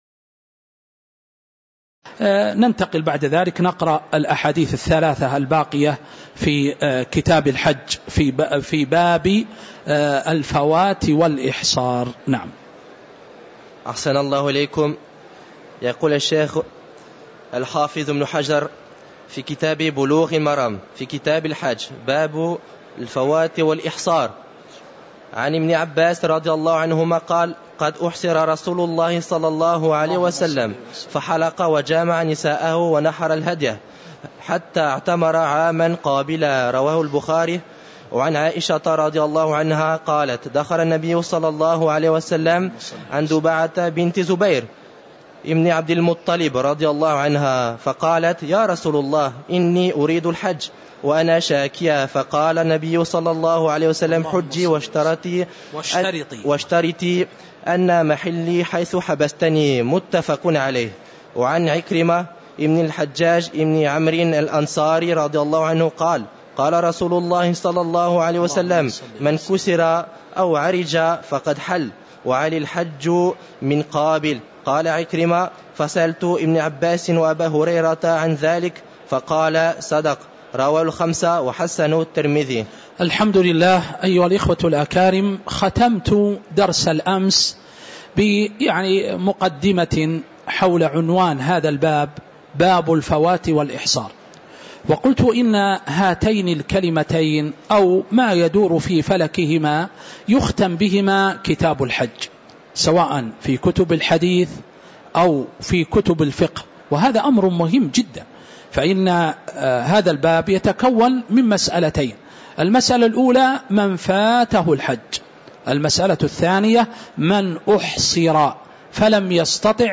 تاريخ النشر ٤ ذو الحجة ١٤٤٥ هـ المكان: المسجد النبوي الشيخ